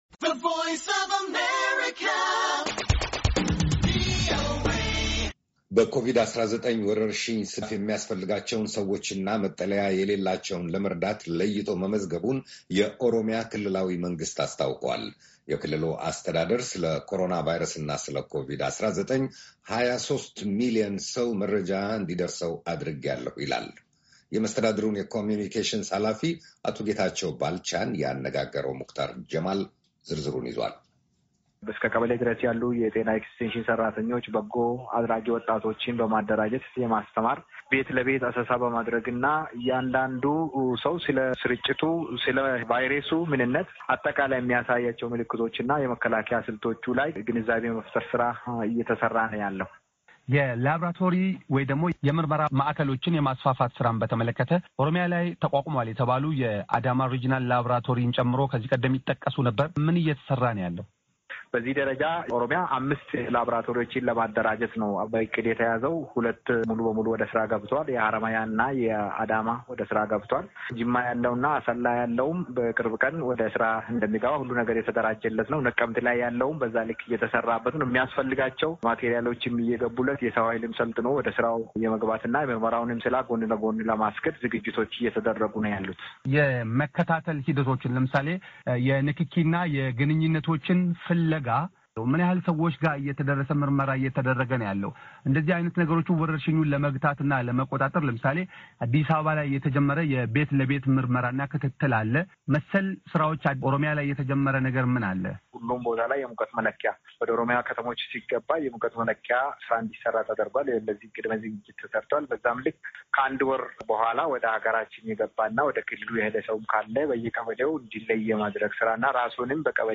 ቃለምልልስ